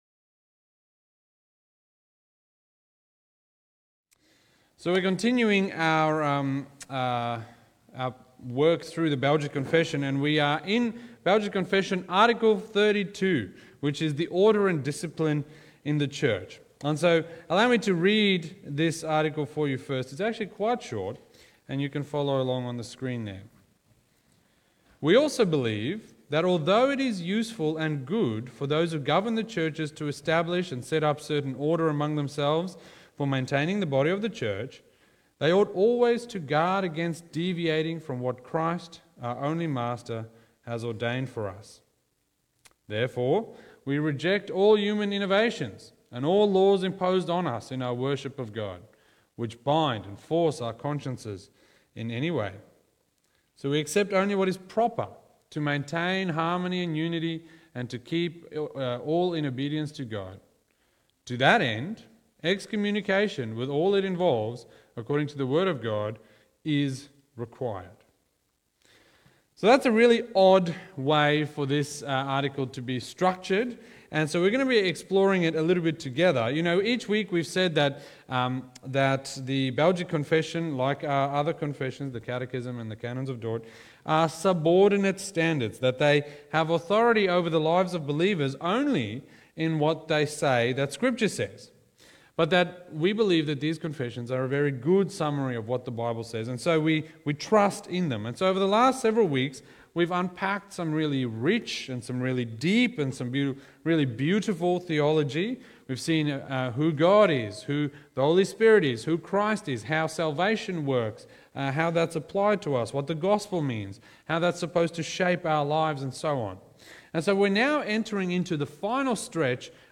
Sermons | Wonga Park Christian Reformed Church